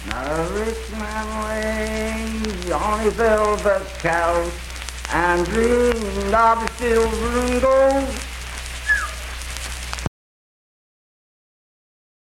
Unaccompanied vocal music performance
Verse-refrain fragment.
Voice (sung)
Spencer (W. Va.), Roane County (W. Va.)